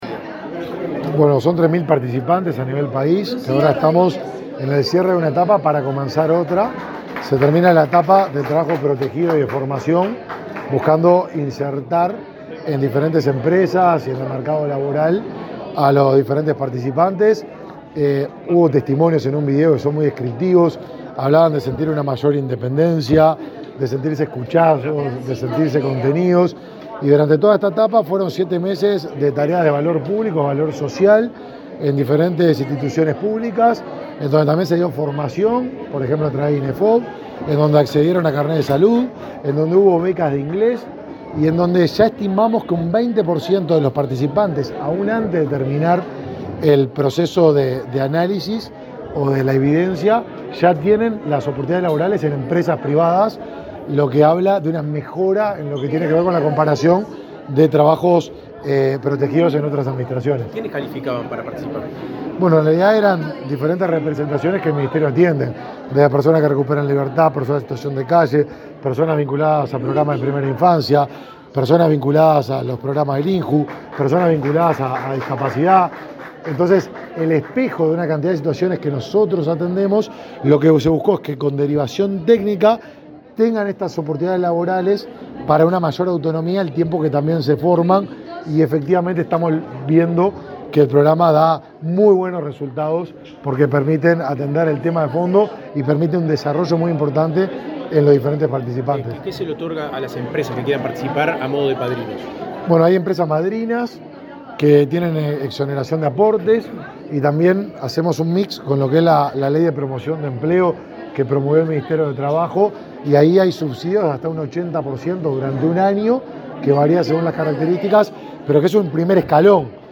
Declaraciones del ministro de Desarrollo Social, Martín Lema
Luego, Lema dialogó con la prensa.